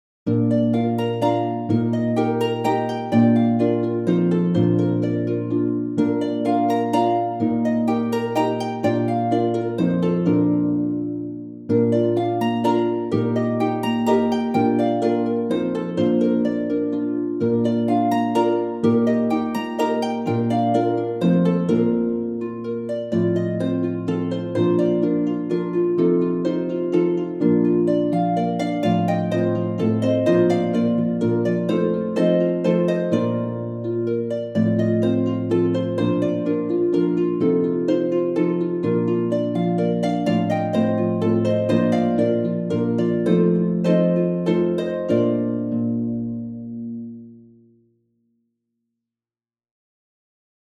for four lever or pedal harps